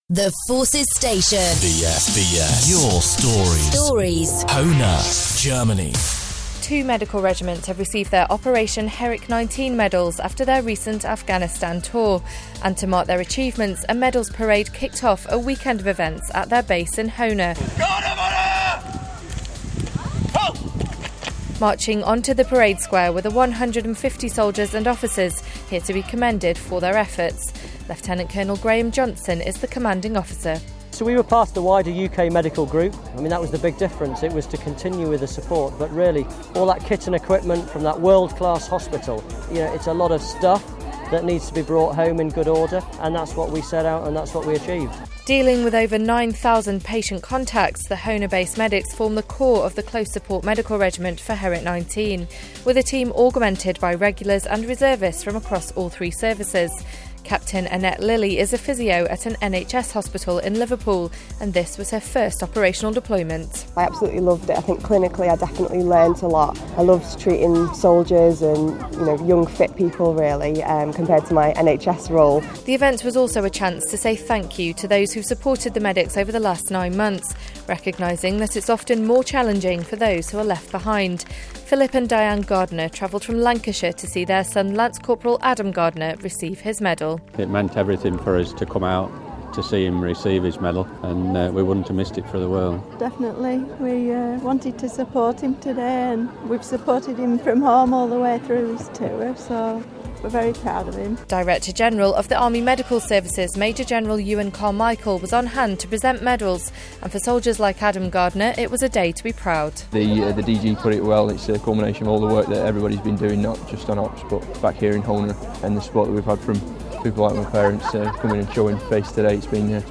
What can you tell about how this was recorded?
And to mark their achievements, a medals parade kicked off a weekend of events at their base in Germany, with friends, families and colleagues joining in to celebrate.